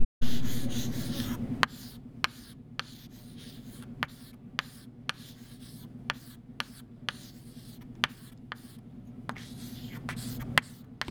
writing-on-chalkboard.wav